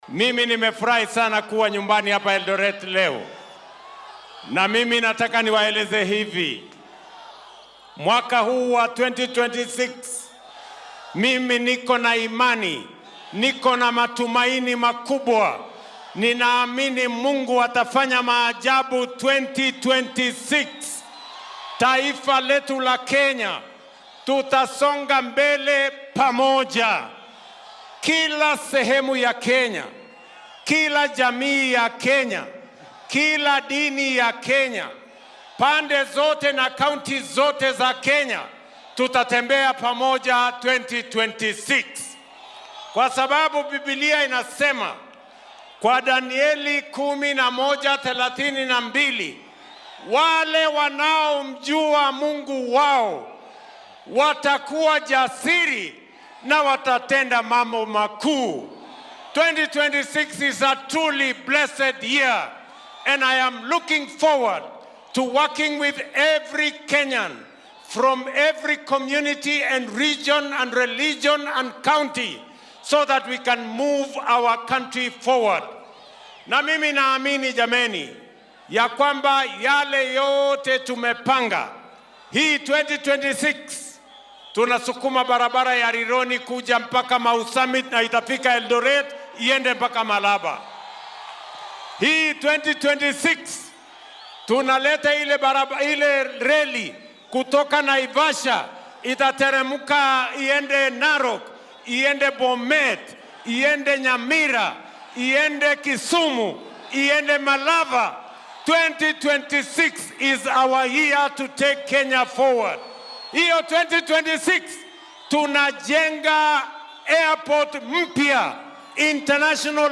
Madaxweyne Ruto, oo khudbaddiisa Sannadka Cusub ka jeedinayay Eldoret, ayaa sheegay in dowladdu ay si dhakhso ah u wadi doonto shaqooyinka waddooyinka socda iyo inay hirgelin doonto waddooyin cusub si loo taageero dhaqdhaqaaqa dhaqaale ee dalka oo dhan.